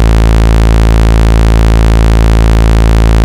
So, I noticed that the sawtooth sounded really raw and I did some detective work and I noticed that my idea that an actual sawtooth wave was happening midway between the triangle and sawtooth markers on the knob.
(all of these are with the filter wide open) I got curious when I was listening to the raw oscs and it wasn't what I was expecting.
The shark tooth is a tri-saw hybrid as found on the minimoog model D.
phattyOSC.wav